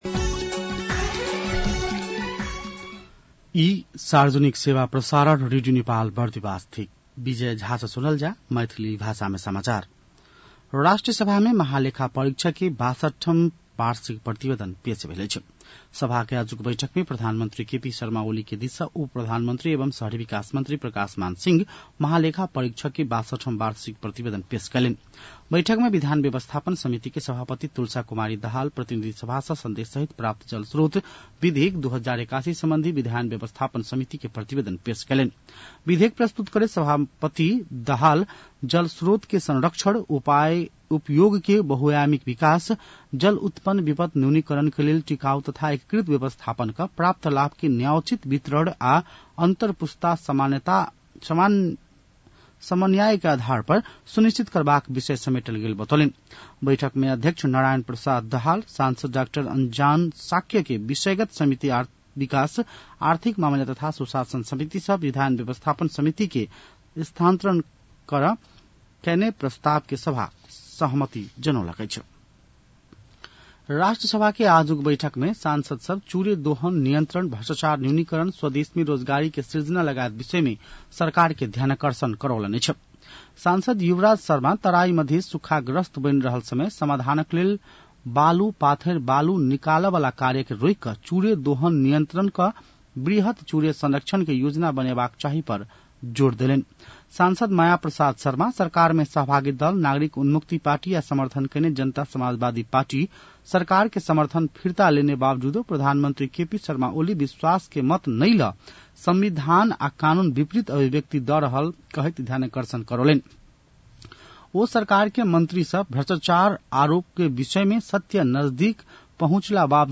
मैथिली भाषामा समाचार : २३ साउन , २०८२
Maithali-news-4-23.mp3